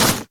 default_grass_footstep.2.ogg